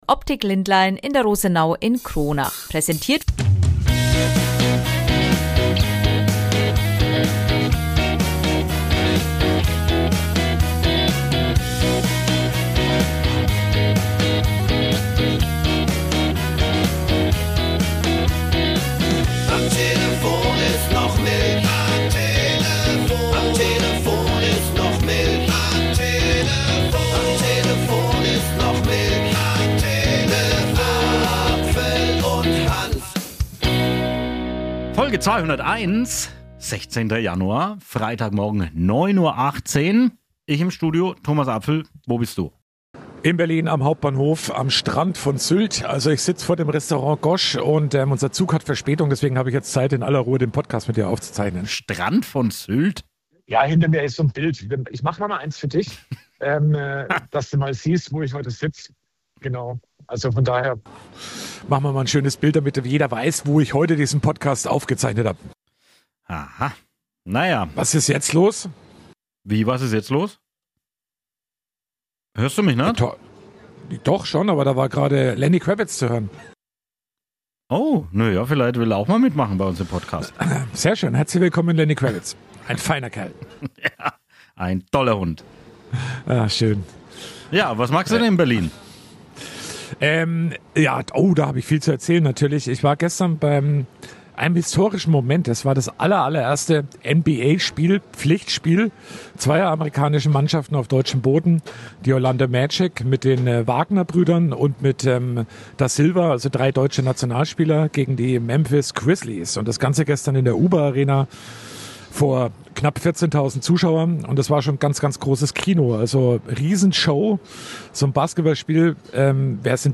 Dazu gibt es viele Berichte und Interviews
mit seinem Küpser Dialekt